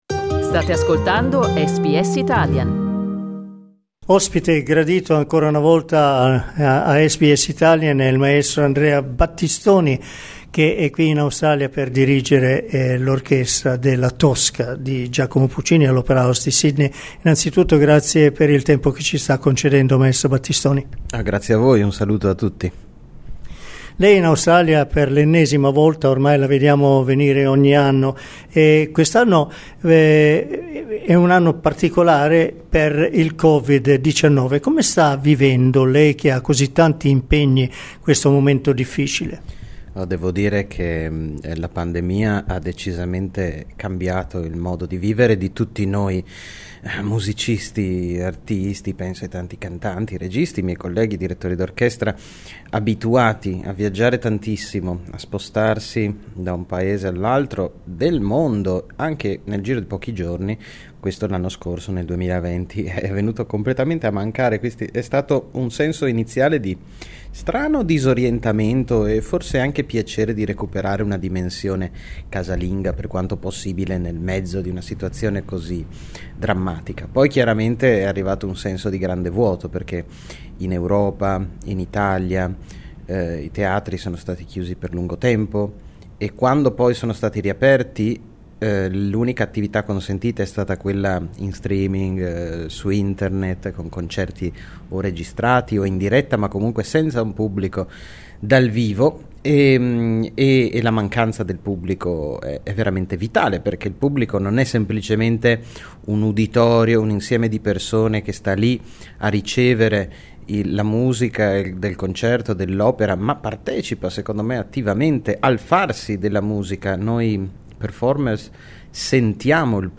Ascolta l'intervista ad Andrea Battistoni: LISTEN TO Andrea Battistoni, nato per la musica SBS Italian 18:24 Italian Le persone in Australia devono stare ad almeno 1,5 metri di distanza dagli altri.